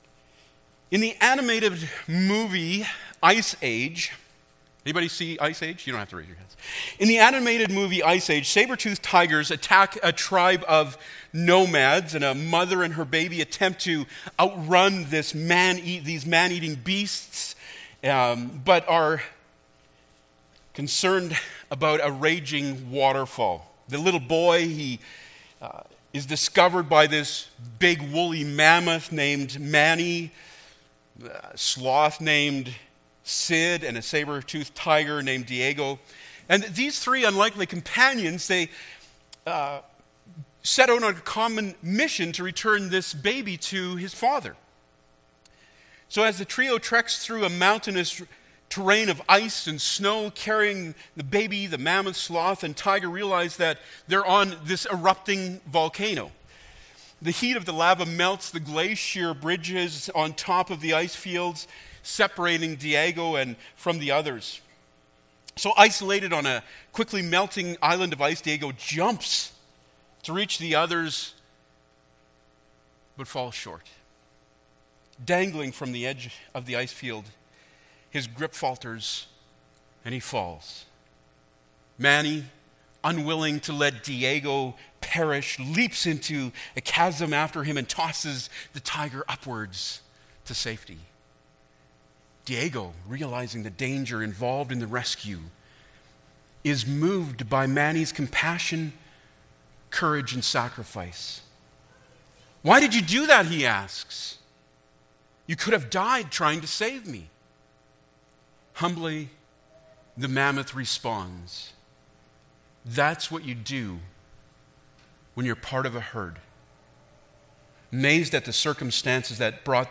Acts 2:42-47 Service Type: Sunday Morning Bible Text